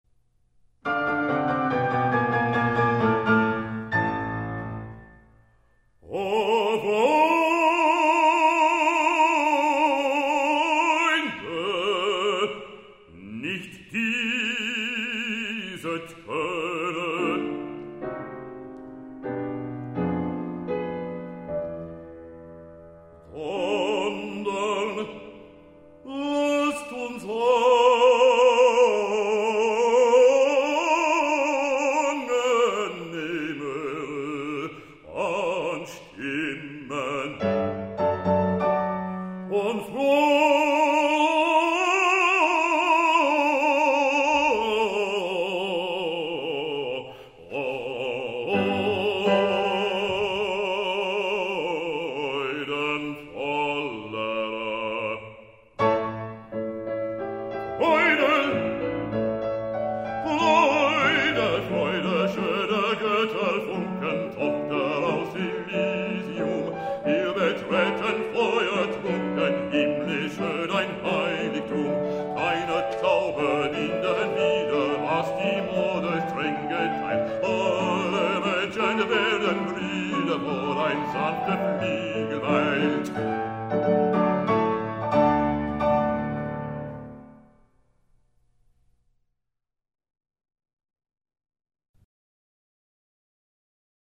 Opera Demos